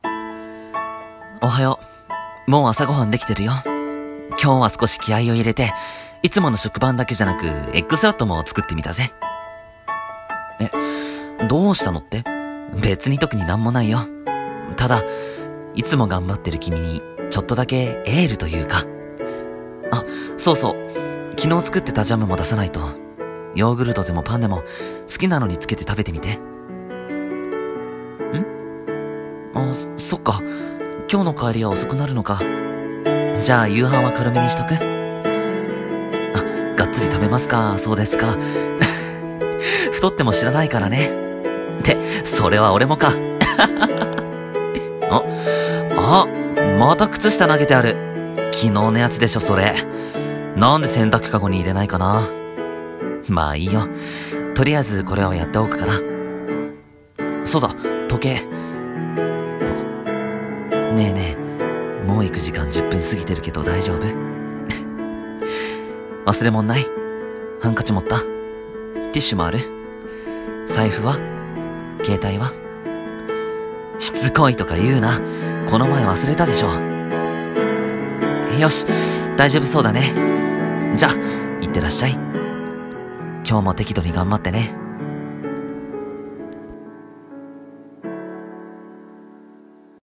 【一人声劇】一日の始まり【声劇台本】